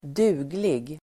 Uttal: [²d'u:glig]